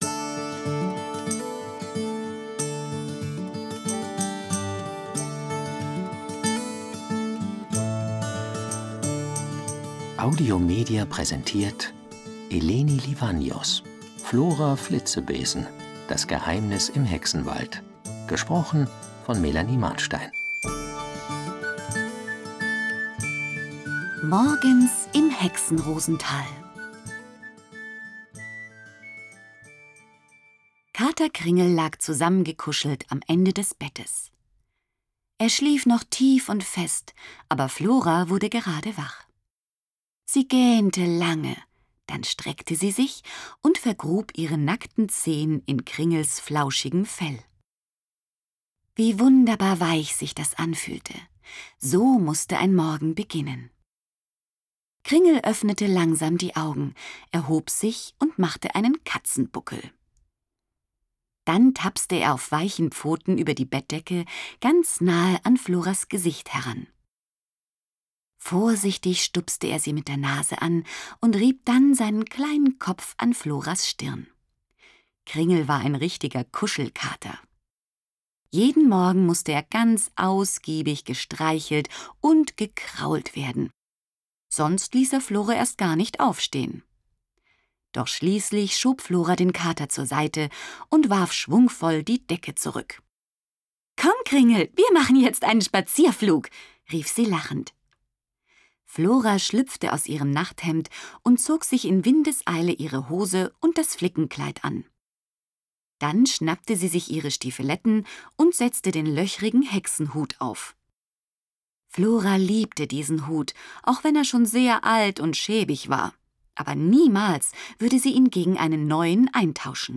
Schlagworte Deutsch • Fantasy; Kinder-/Jugendliteratur • Flora • Freunde • Hexe; Kinder-/Jugendliteratur • Hexen • Hexenmädchen • Hexenrosental • Hexer • Hörbuch; Lesung für Kinder/Jugendliche • Mädchen; Kinder-/Jugendliteratur • magische Tiere • Plan • Spinne • Zusammenhalt